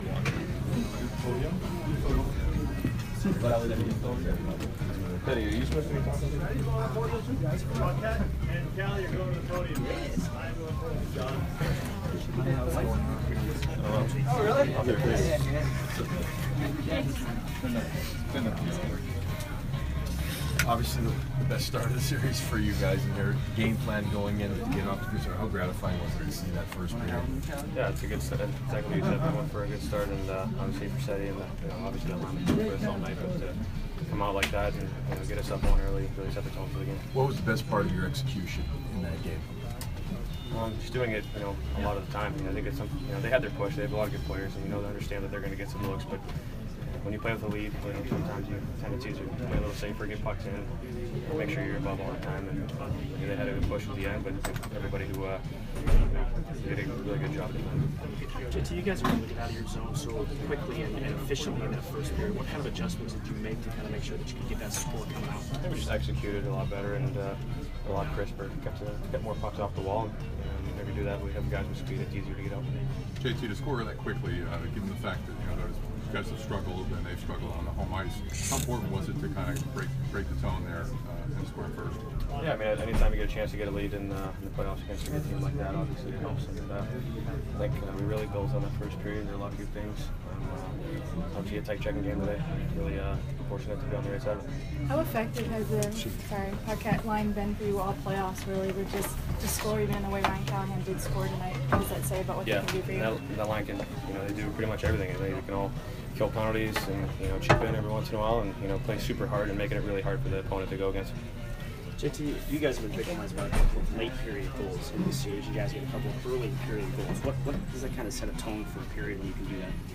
J.T. Miller post-game 5/19